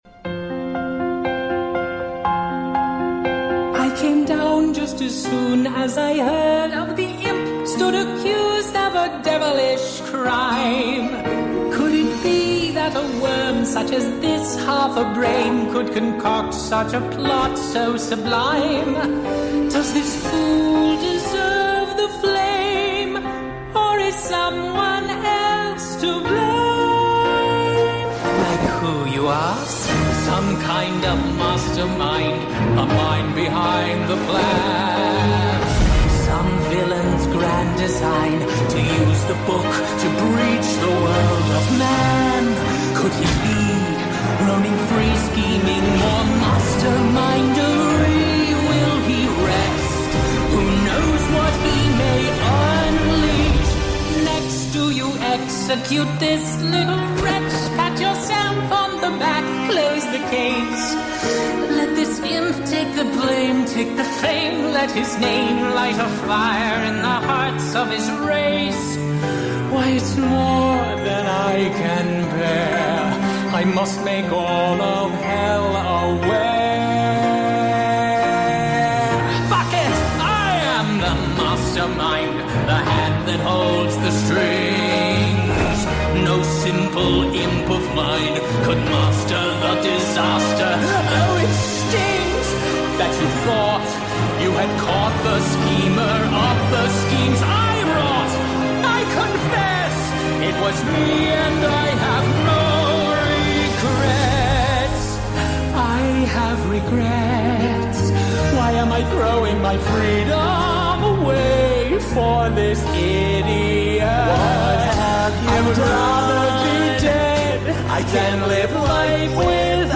#8Daudio